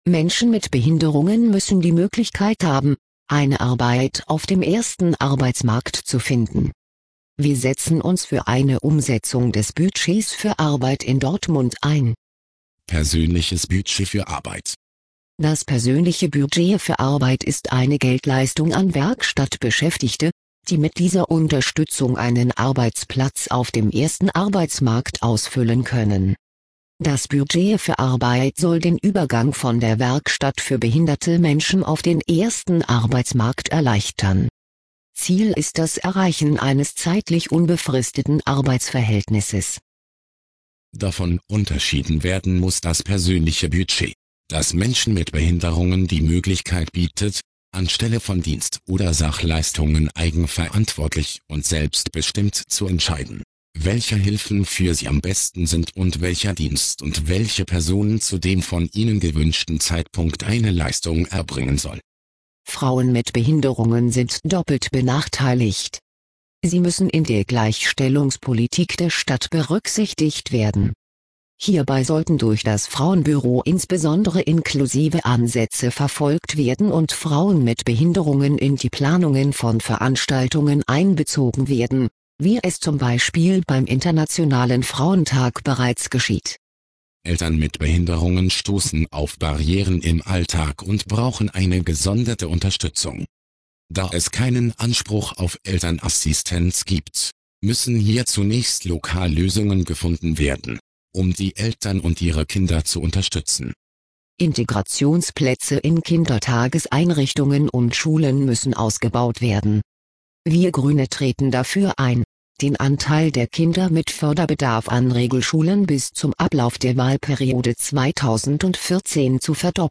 Auszüge aus unserem Kommunalwahlprogramm 2009 als Sprachversion